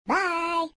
roblox-bye-sound-effect-made-with-Voicemod-technology.mp3